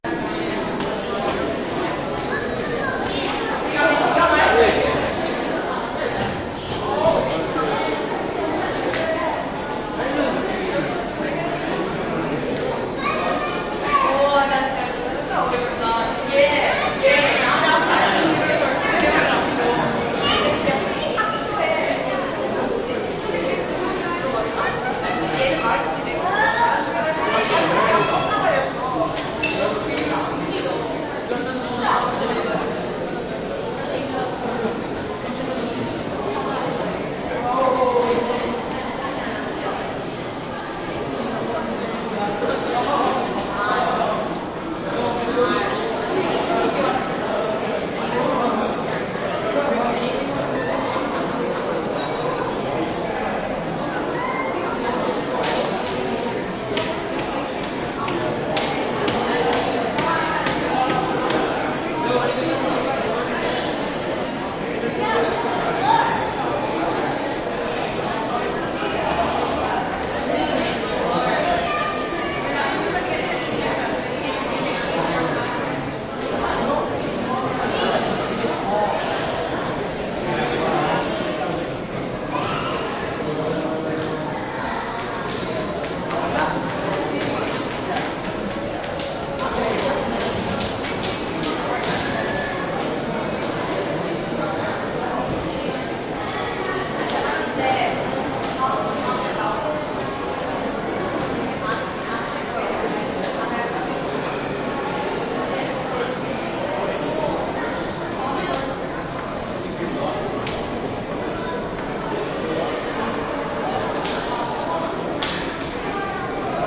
大气 " atmos博物馆大型画廊
描述：博物馆的气氛。Roland R26内部全向+XY话筒混合
标签： 大廊 博物馆 大气
声道立体声